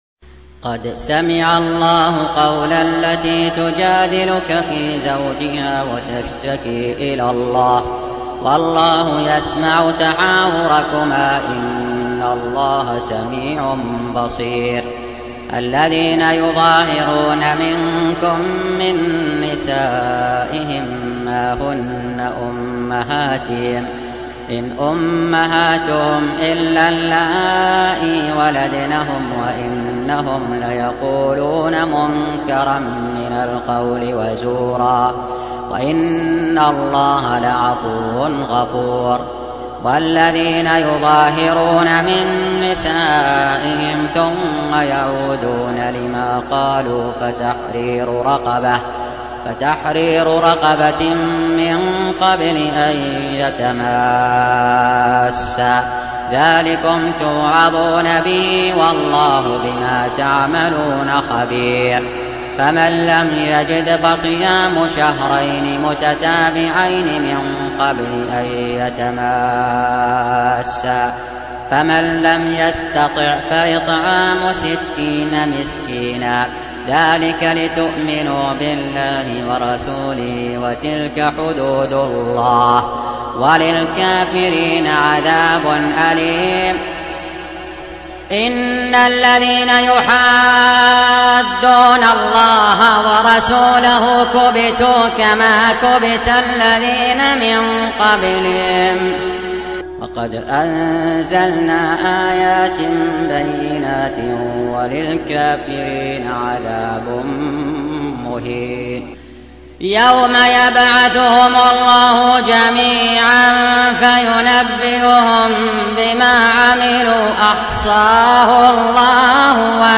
Surah Repeating تكرار السورة Download Surah حمّل السورة Reciting Murattalah Audio for 58.